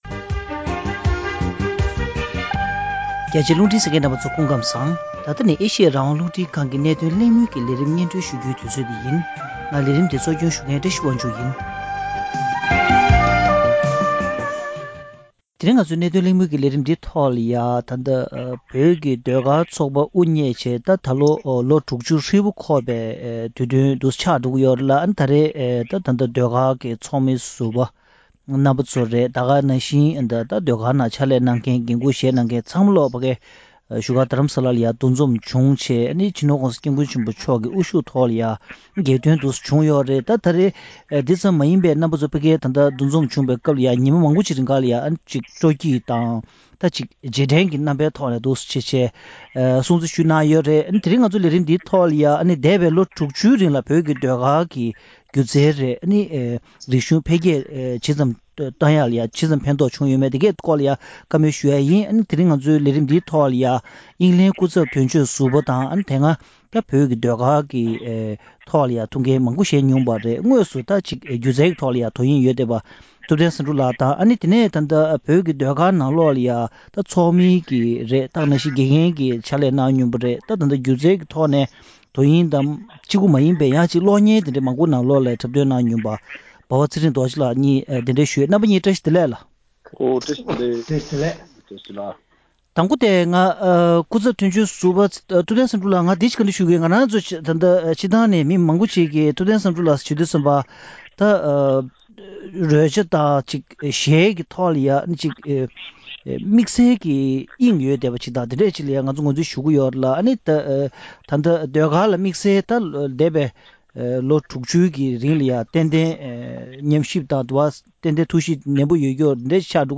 བོད་ཀྱི་ཟློས་གར་ཚོགས་པ་དབུ་བརྙེས་ནས་འདི་ལོ་ལོ་༦༠འཁོར་བ་དང་བསྟུན། འདས་པའི་ལས་དོན་དང་བྱུང་བ་ལ་བསྐྱར་ཞིབ་ཐད་གླེང་མོལ་ཞུས་པ།